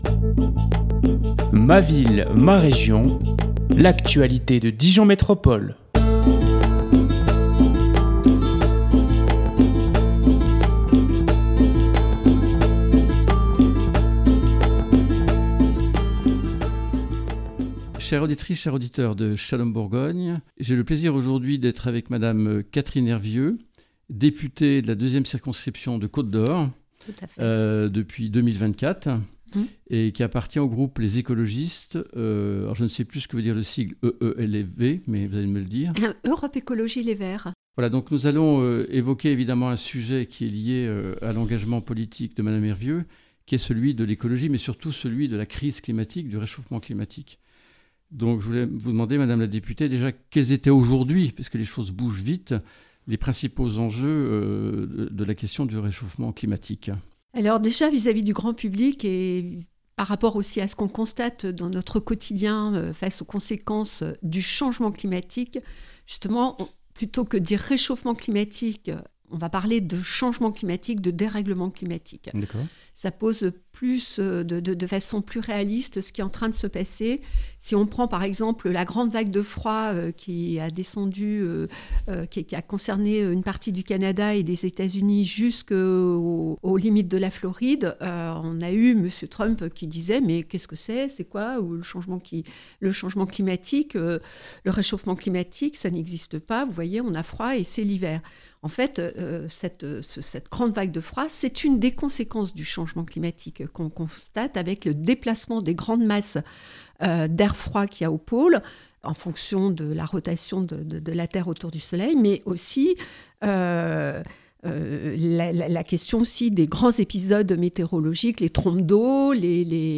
« Shalom Bourgogne » reçoit Catherine Hervieu députée de la deuxièmecirconscription de Côte d’Or depuis juillet 2024, membre du parti « LesEcologistes ».